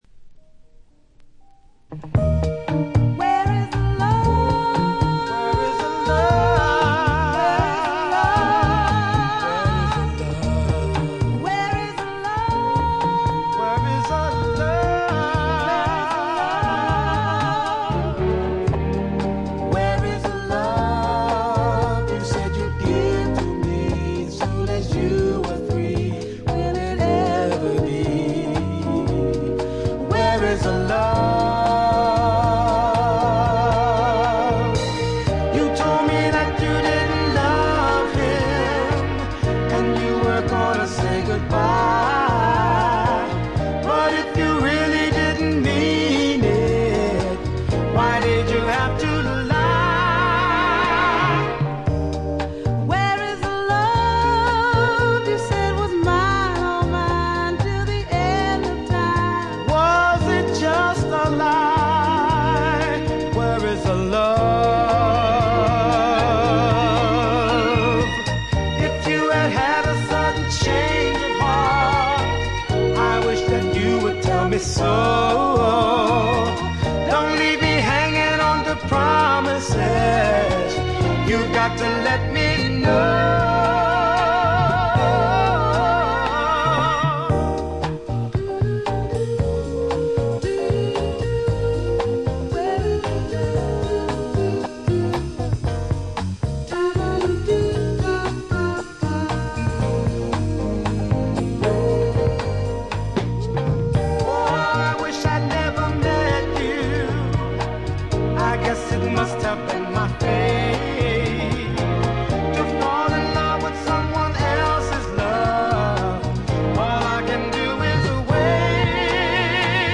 部分試聴ですが、ほとんどノイズ感無し。
頂点を極めた二人の沁みる名唱の連続で身体が持ちませんね。
試聴曲は現品からの取り込み音源です。